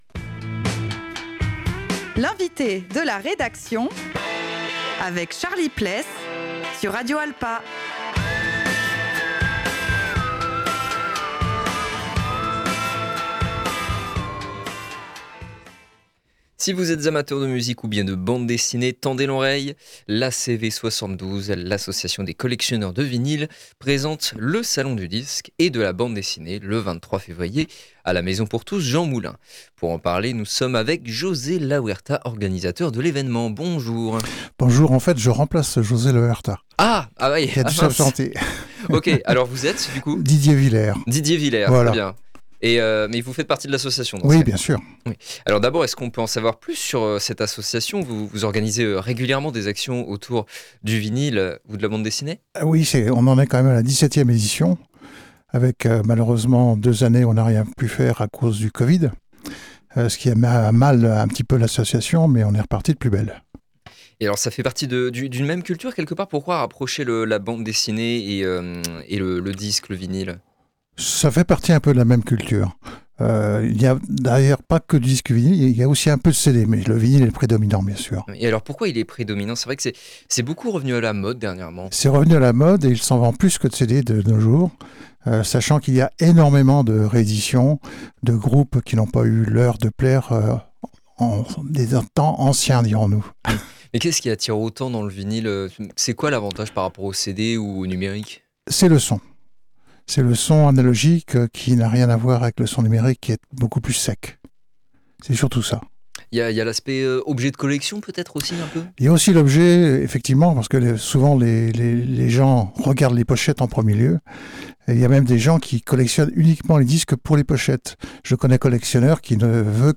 107.3 Le Mans